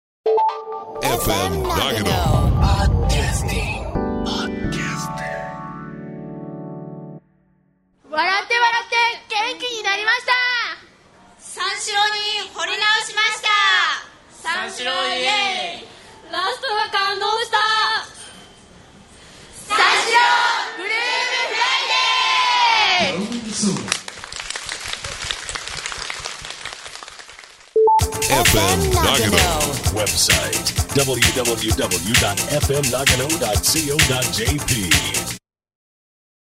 3/17(金)松本パルコで公開生放送を終え、
会場の皆さんとともに作成した番宣です！